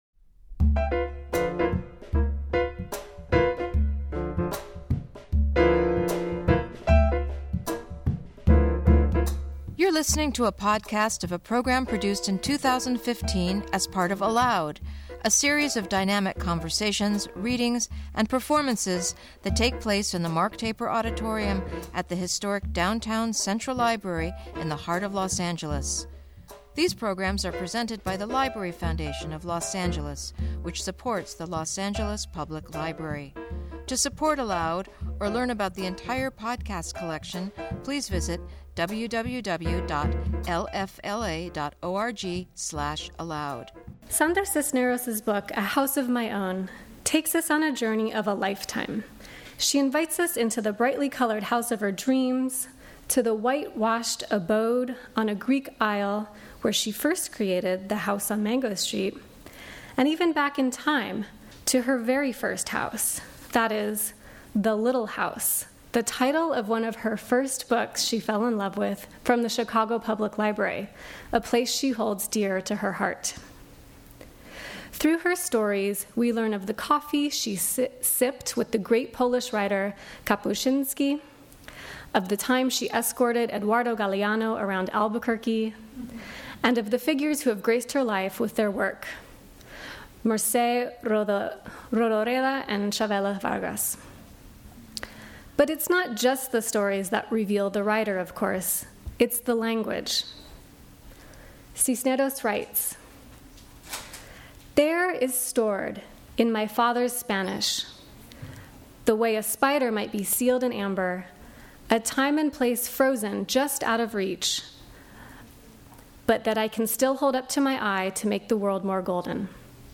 Reflecting on the private journey of a life in writing, ALOUD welcomes Cisneros to the stage for a reading and conversation.